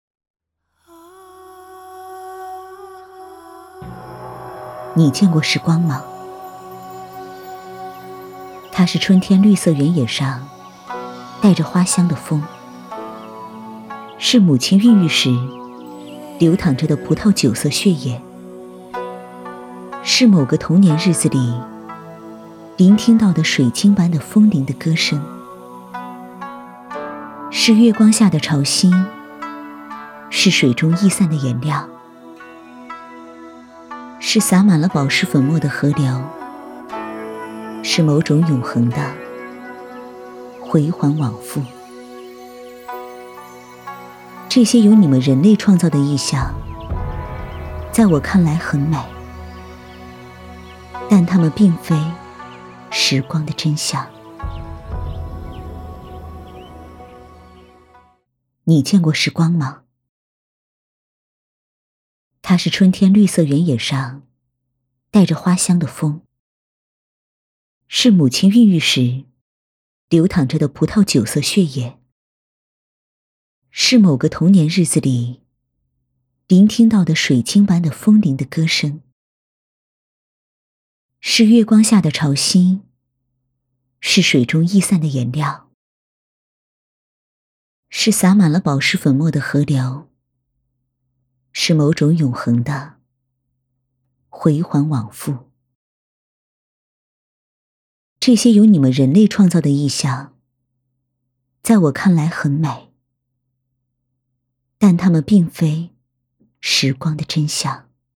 v385-【独白】时光
女385温柔知性配音 v385
v385--独白-时光.mp3